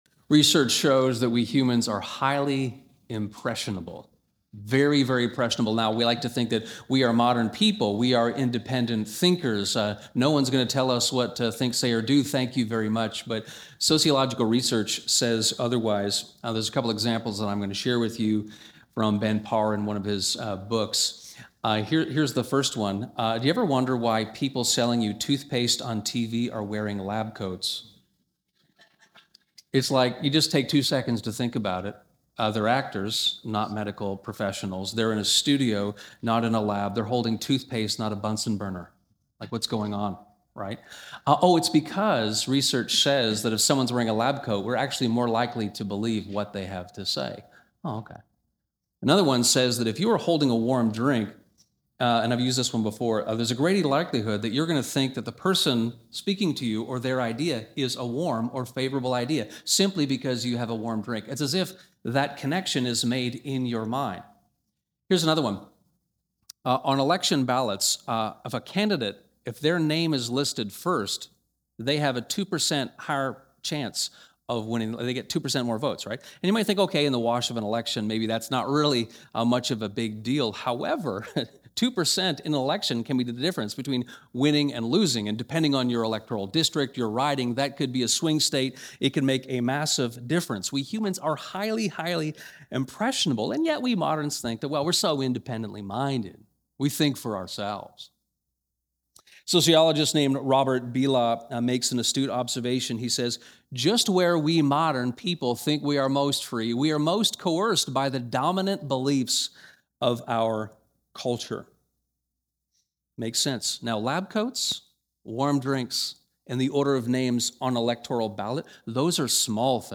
This sermon on 3 John unpacks this short letter, takes seriously our impressionability as modern people, and encourages us to imitate good, not evil.